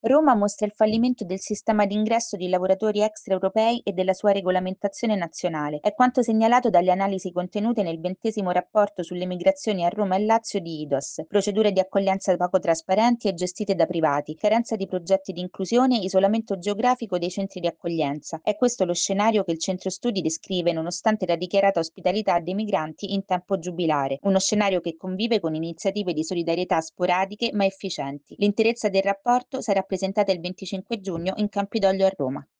Nella Capitale mancano efficaci progetti di inclusione delle persone migranti. Il servizio